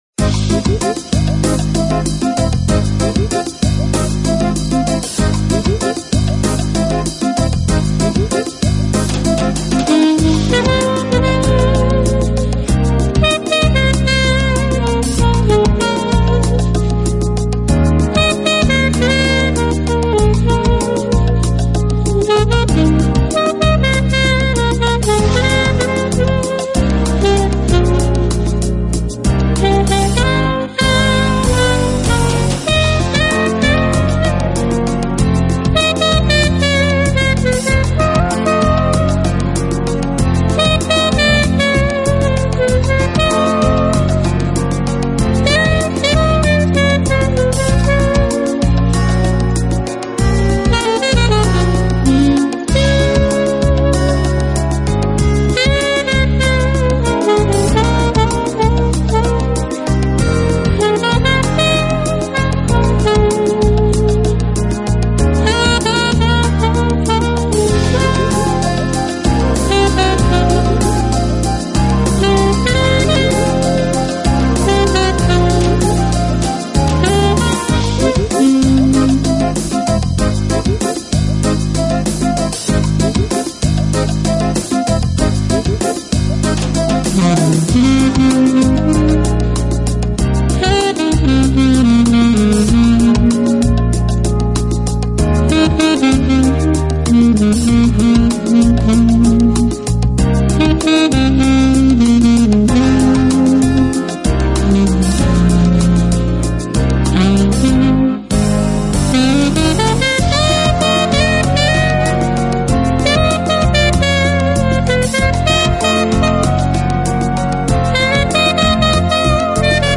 Genero/Genre: Smoth Jazz/Orchestral Pop
代达到顶峰，据说他是最善于催情的萨克斯演奏家，“演绎出的曲目有如化